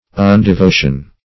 Undevotion \Un`de*vo"tion\